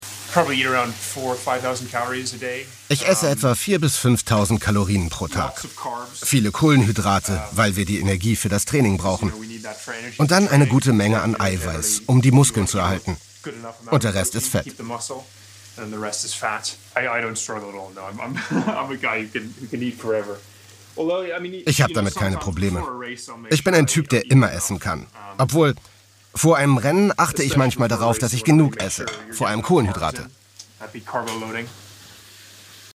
sehr variabel
Mittel minus (25-45)
Doku